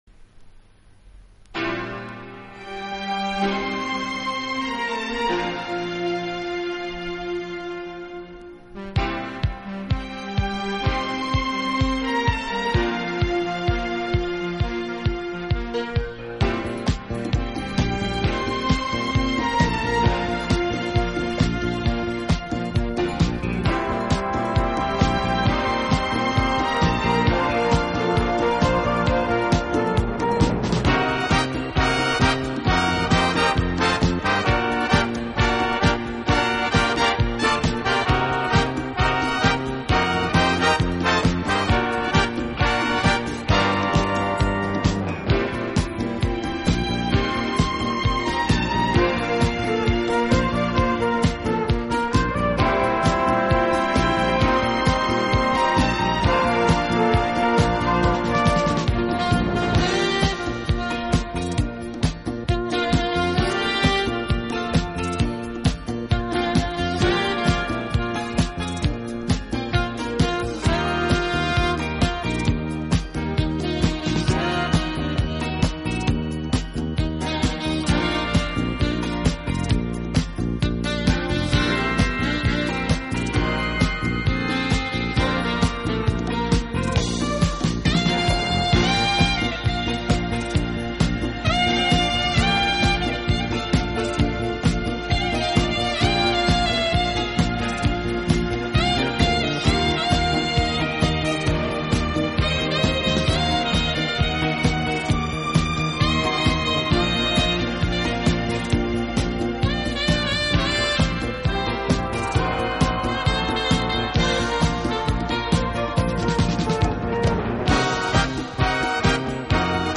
和富于歌唱性的民族特征便在乐队清晰而淋漓的演奏中一展无疑。